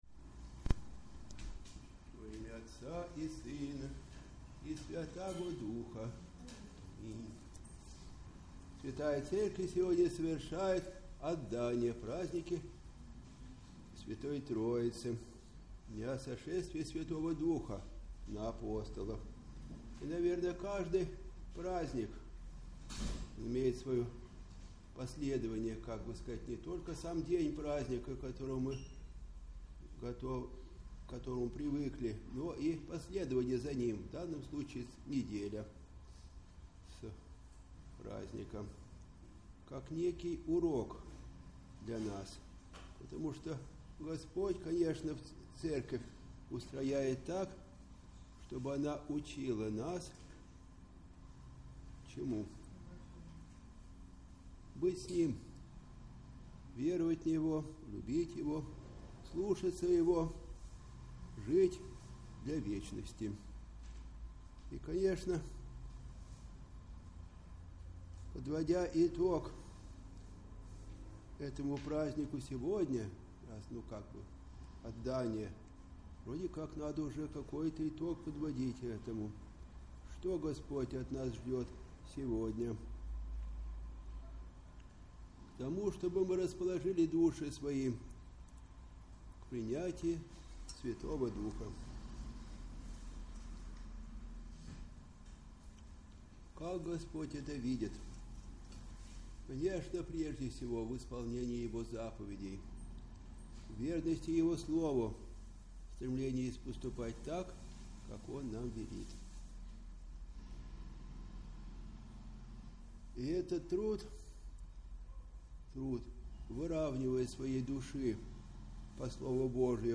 Проповедь прот.
в день отдания праздника Пятидесятницы